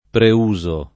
preuso